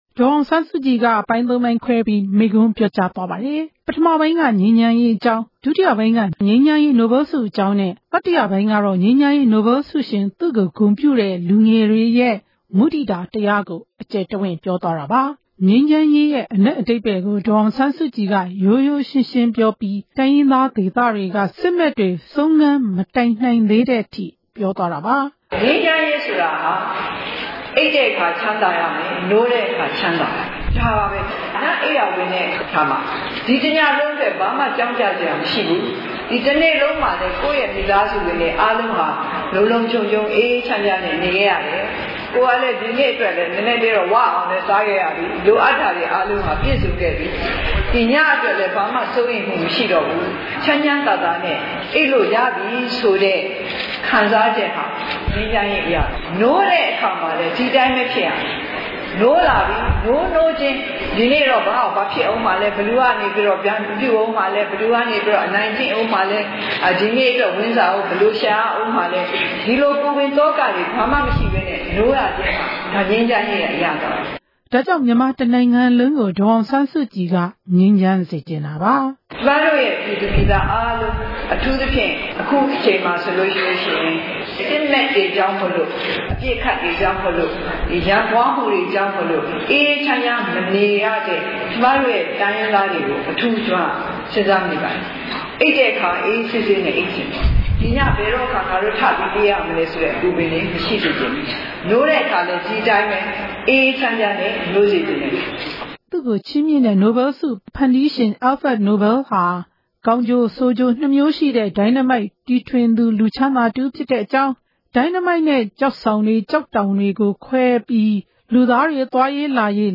ဒေါ်အောင်ဆန်းစုကြည် တက်ရောက်ပြီး မိန့်ခွန်းပြောကြားခဲ့တဲ့ ဒီကနေ့ အခမ်းအနားကို ရန်ကုန် ကြည့်မြင်တိုင်မြို့နယ် ရွှေညဝါ သာဓု ပရိယတ္တိ စာသင်တိုက်မှာ ကျင်းပခဲ့တာပါ။